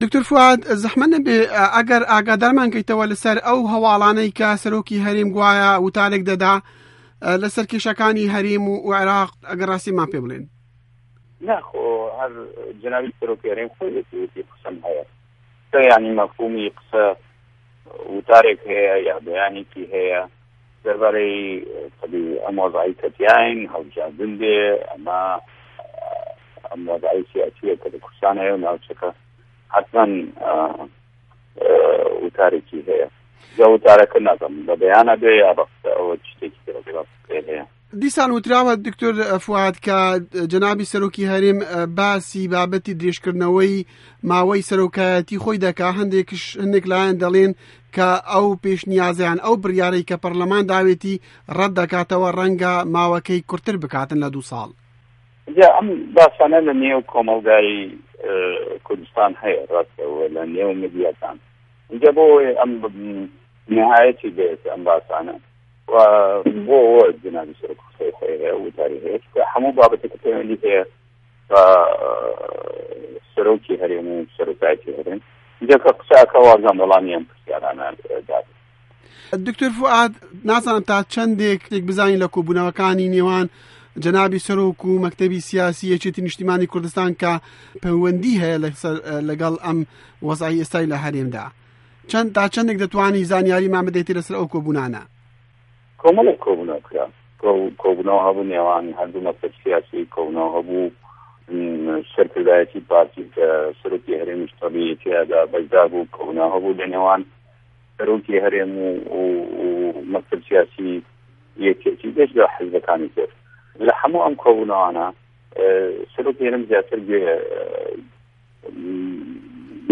وتووێژی دکتۆر فوئاد حسێن